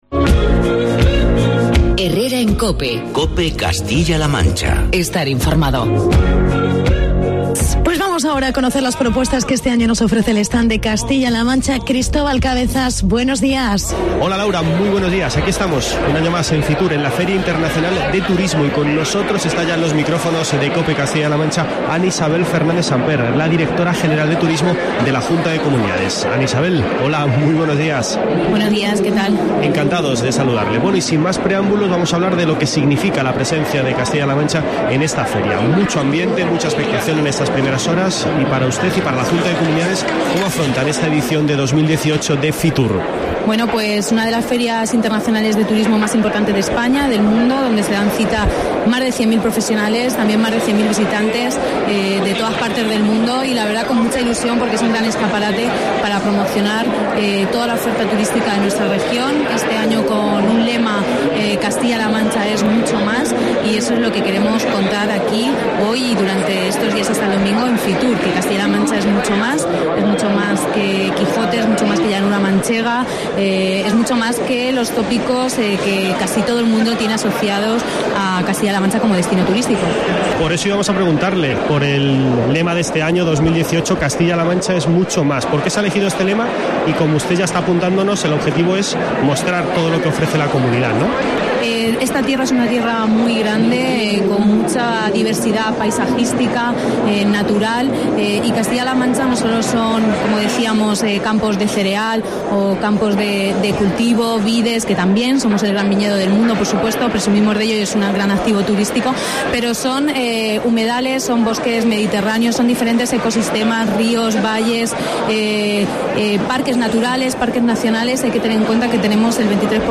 Entrevista con Ana Fernández. Directora General de Turismo CLM